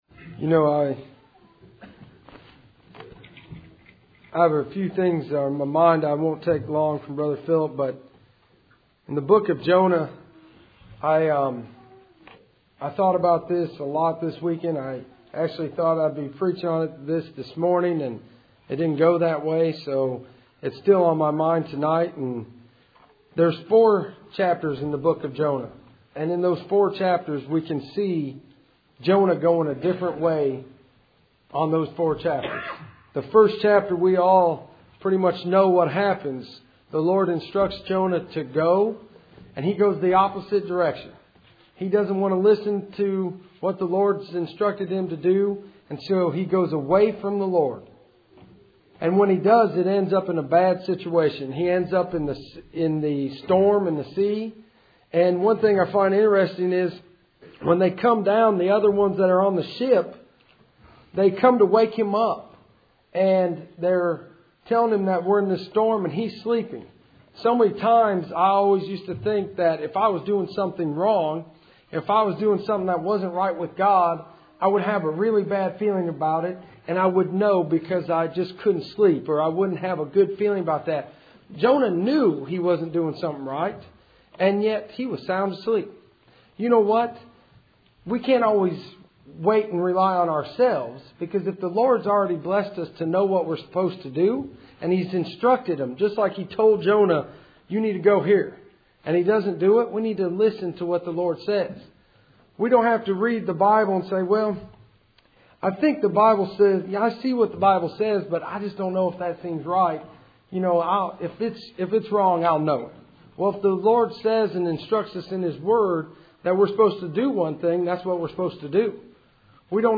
Cool Springs PBC Sunday Evening %todo_render% « Jonah Part 2